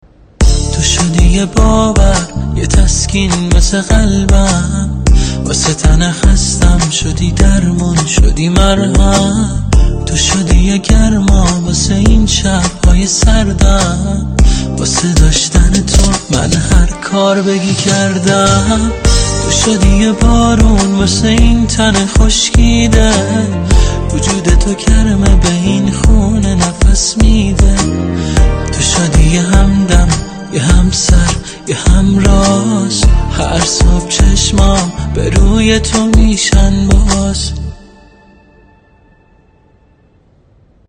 پخش آنلاین دمو همین موزیک
خیلی خوبه صدات قشنگ و با احساسه دمت گرم